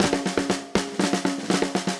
Index of /musicradar/80s-heat-samples/120bpm
AM_MiliSnareA_120-03.wav